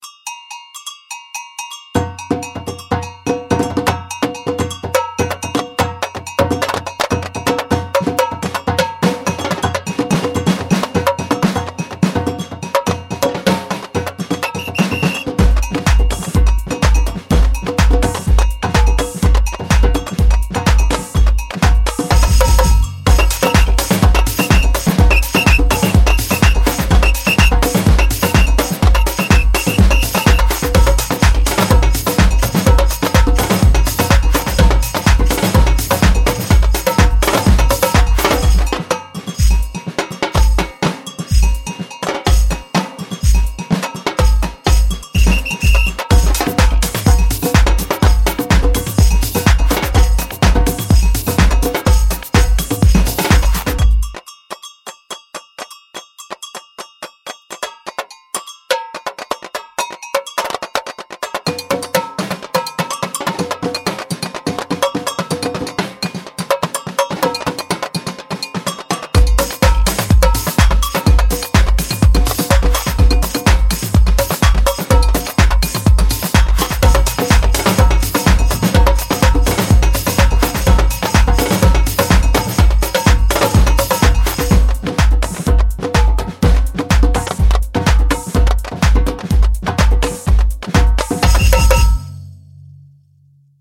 Wavesfactory Samba Drums是一款为Kontakt制作的采样音源库，它包含了一系列来自巴西的民间打击乐器。
所有的乐器都在一个完全干燥的录音室环境中使用2个麦克风位置录制，用户可以独立地混合和控制不同的麦克风信号。
- Surdo 18英寸，20英寸和22英寸
- Tamborim
- Agogó